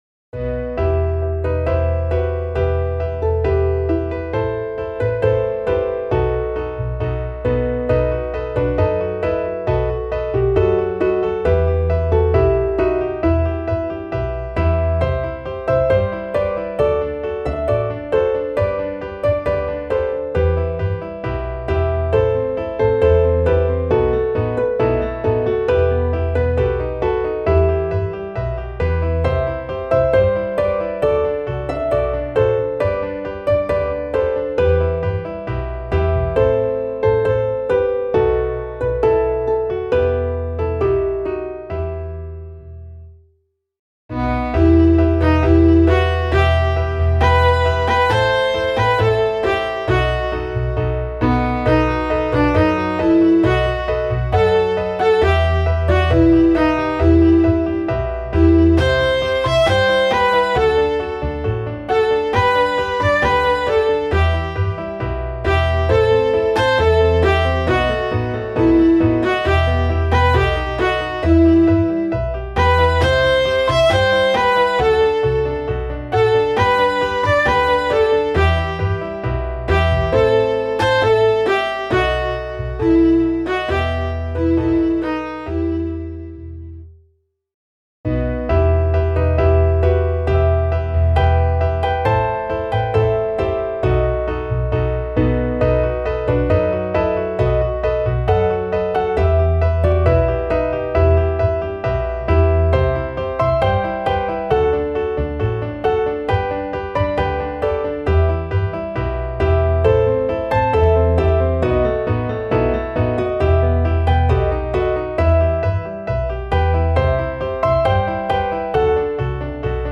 Klaviersatz
midi_fahrtenbrueder_klavier_320.mp3